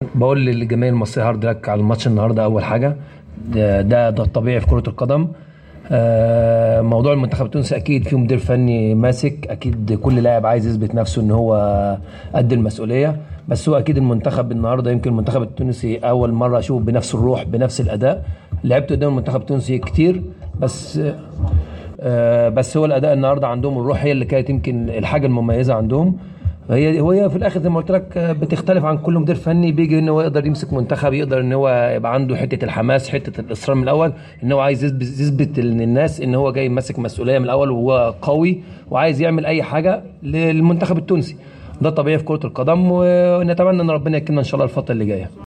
أكد حارس المنتخب المصري عصام الحضري في تصريح لجوهرة أف أم إثر اللقاء الذي جمع المنتخب التونسي بنظيره المصري أنه لأول مرة يشاهد المنتخب التونسي يلعب بهذه الروح العالية والأداء المتميز .